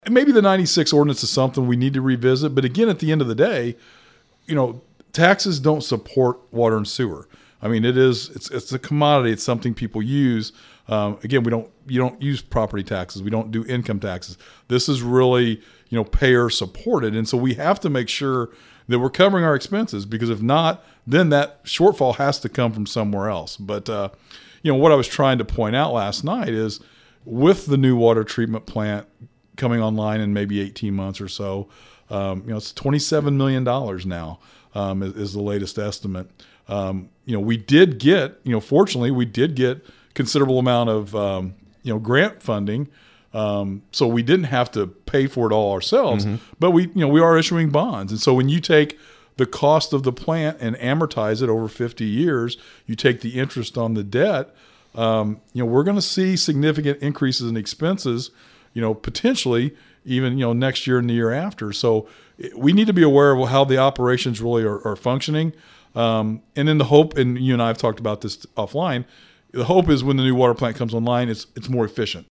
Mayor Knebel says that is something they have to think about and he also explains they have to keep up with any increase in water production rates because they want to make sure the money coming in matches what it costs to produce water.